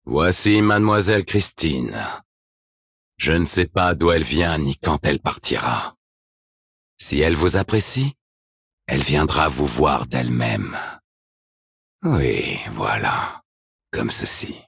Oh, he's AGGRESSIVELY French.
A bit deeper voice compared to his English performance, and even more so his JP one. He feels more menacing in a way.